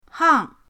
hang4.mp3